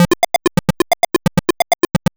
retro_synth_beeps_groove_01.wav